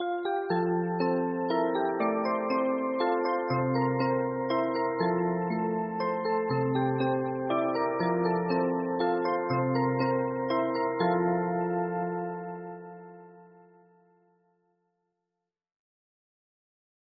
OPEN ON CLOSE OFF STOPPER OVER 18 NOTE MUSICAL MOVEMENT
Music Feature: Mechanical Device Playing Music Play Method: Spring driven,Key wind
1)Standard size 18 note musical movement
The Core part - Comb is made of special steel and by our unique technology and skills